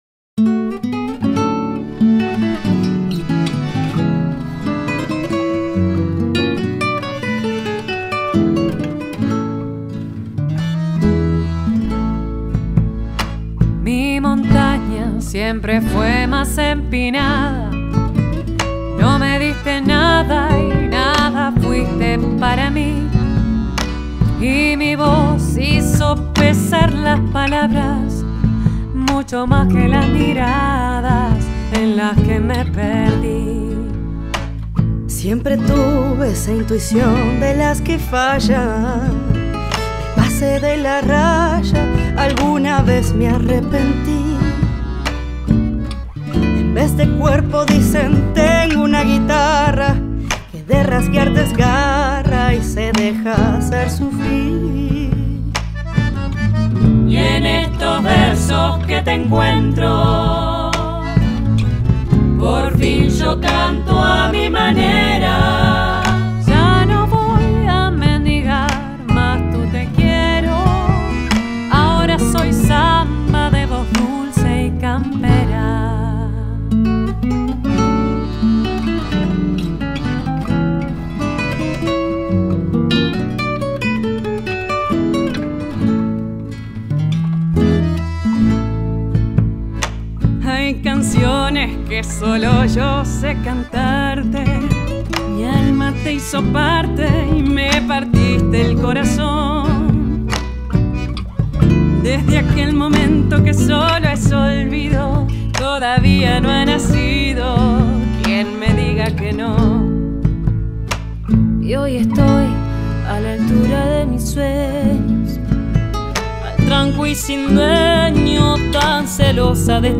Carpeta: Folklore mp3